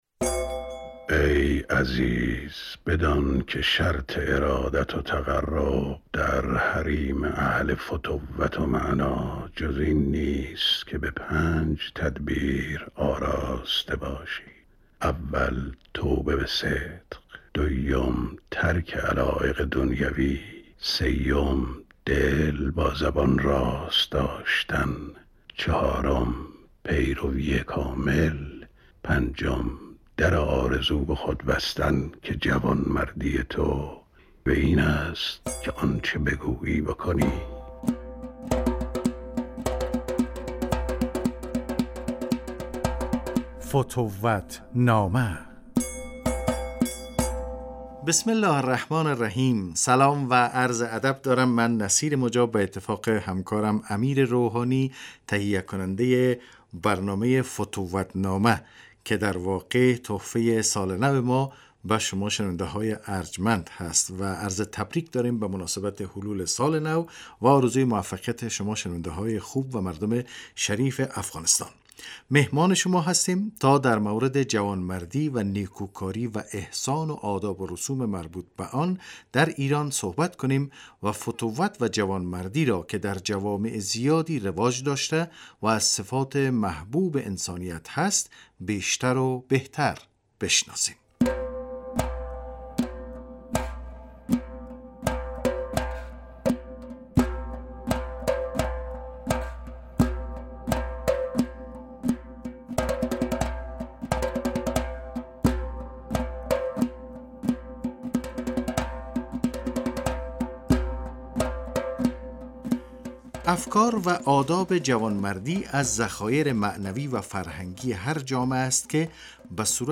این برنامه تولید شبکه رادیو دری صداوسیمای جمهوری اسلامی ایران است که روزهای پنجشنبه از این رادیو پخش میشود.